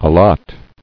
[al·lot]